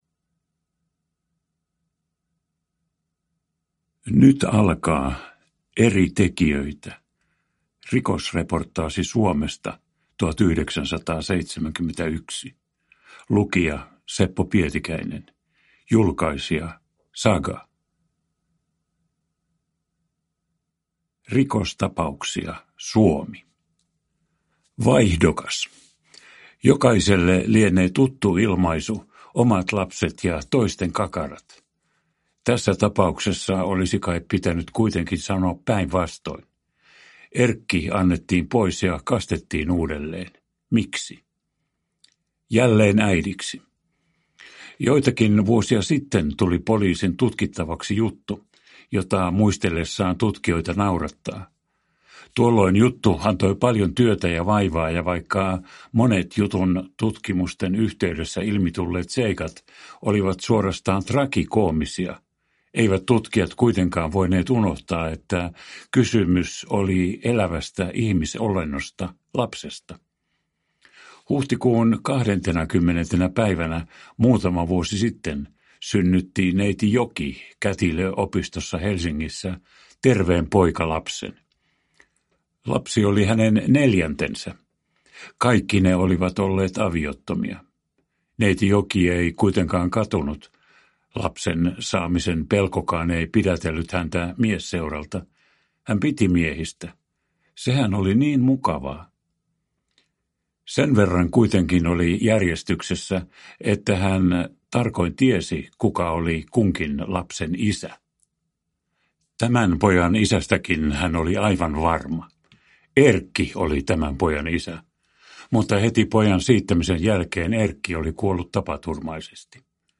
Rikosreportaasi Suomesta 1971 (ljudbok) av Eri tekijöitä